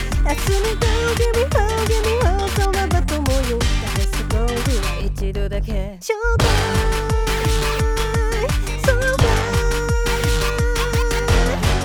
実際に私が歌ってみたハモリ有り無しの素音源(mix前のもの)を用意したので聴き比べてみて下さい。
▼ハモリ無し